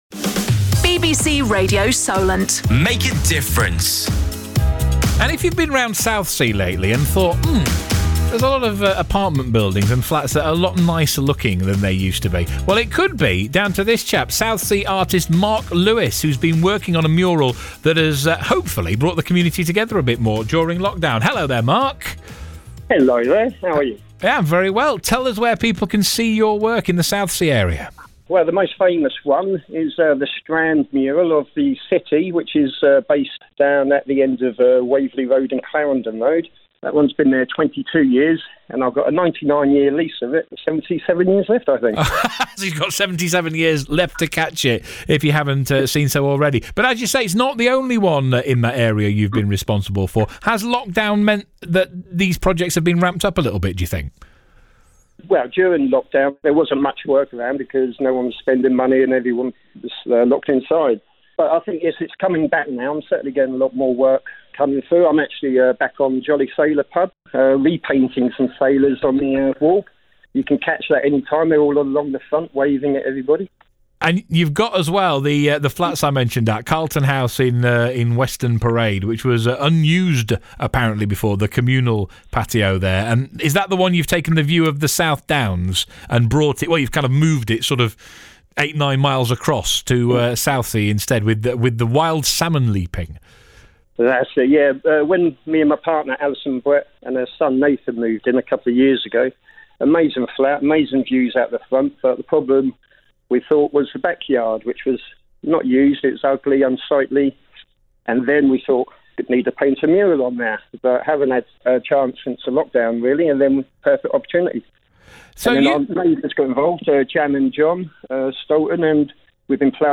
Recording of my Radio Solent appearance